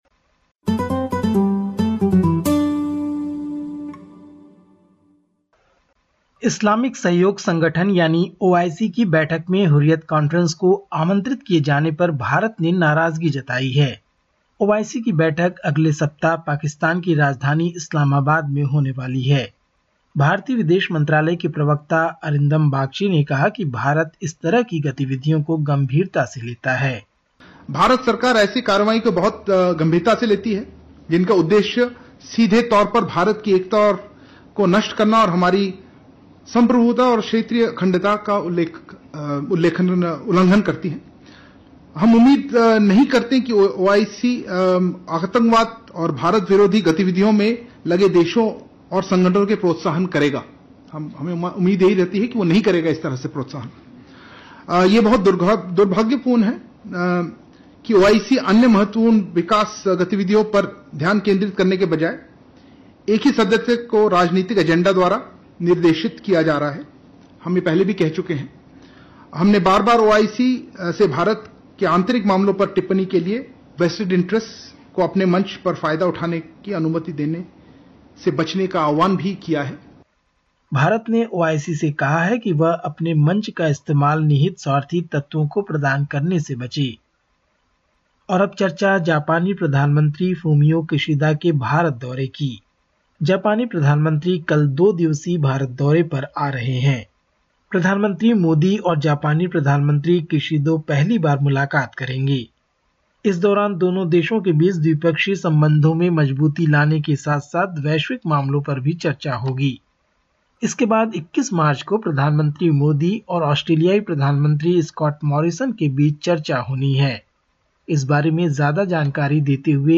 India report: Australian and Indian Prime Ministers to hold second virtual summit on 21 March
Listen to the latest SBS Hindi report from India. 18/03/2021